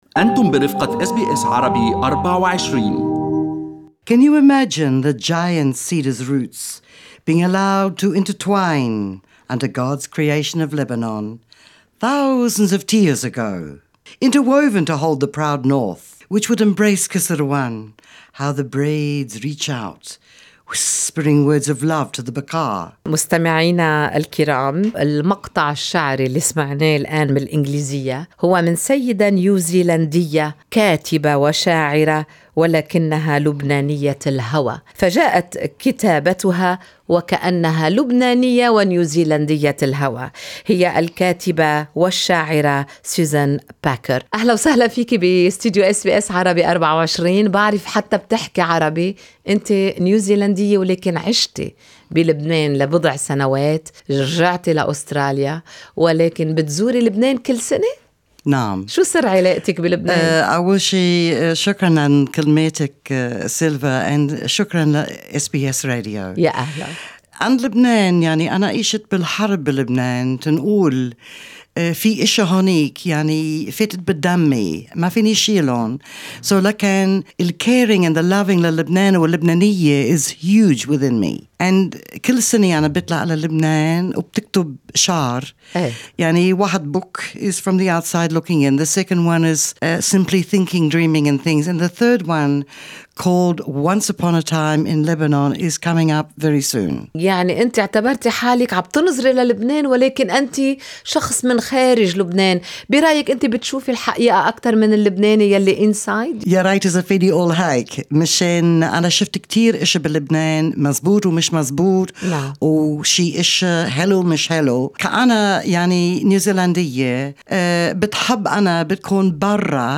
استمعوا إلى اللقاء الصوتي المرفق بالصورة مع الشاعرة التي تحدثت بصراحة عن التحديات التي تواجه لبنان اليوم على الصعيد السياسي والبيئي.